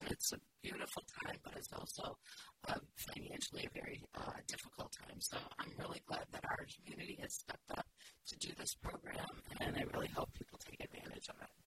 Kalamazoo Public Schools board vice president Carol McGlinn says she is sure that some of their parents should be able to take advantage of the program, telling trustees last night it lifts one burden from new families that can be a crisis for some.